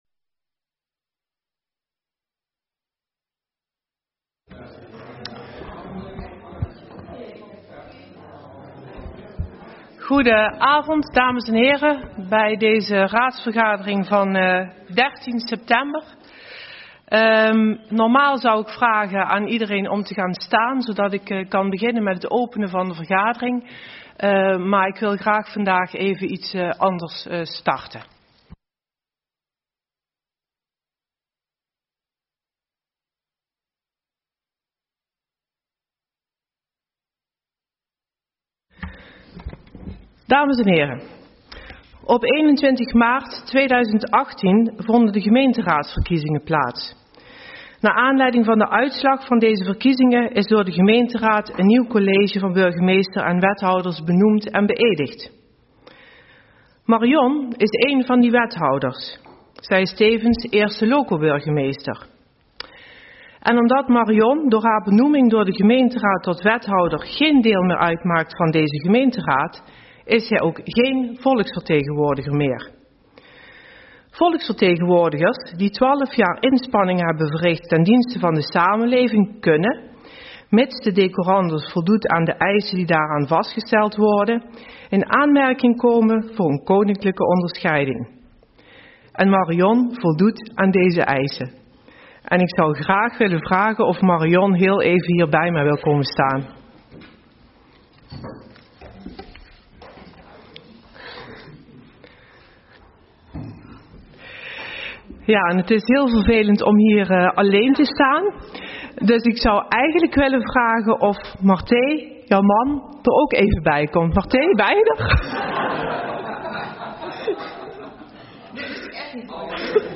Locatie Raadzaal